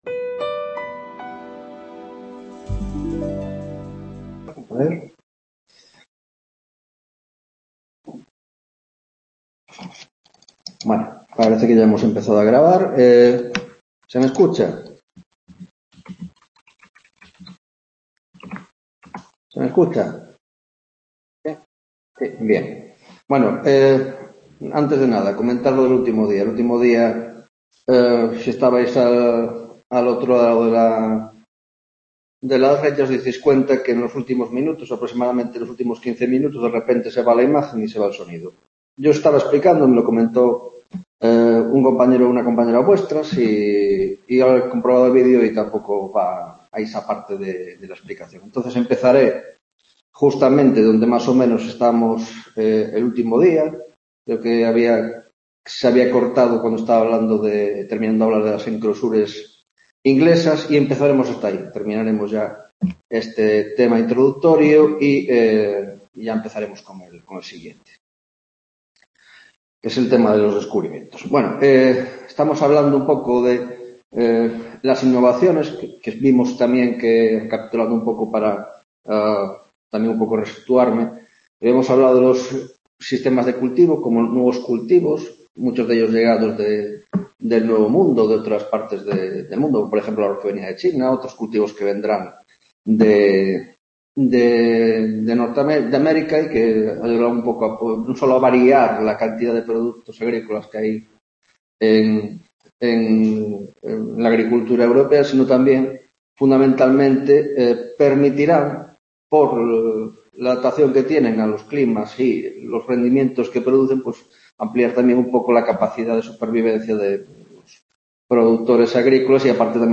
3ª Tutoria de Historia Moderna, Grado de Antropología - Introducción 2: Economia